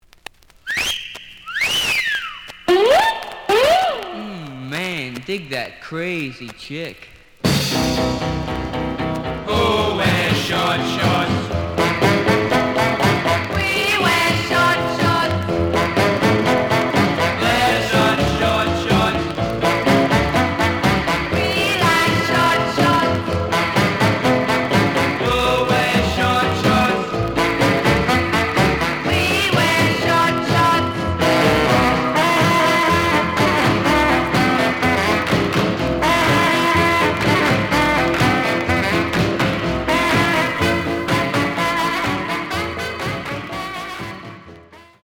The audio sample is recorded from the actual item.
●Genre: Rhythm And Blues / Rock 'n' Roll
Some periodic noise on first half of both sides.)